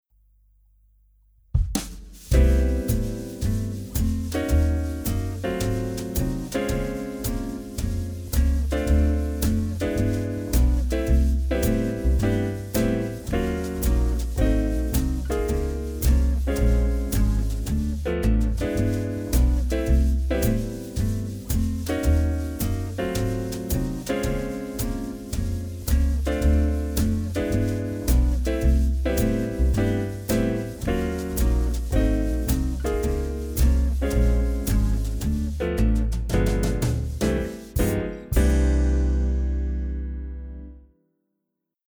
Akkordprogression med modulation og gehørsimprovisation:
Lyt efter bassen, der ofte spiller grundtonen.
C instrument (demo)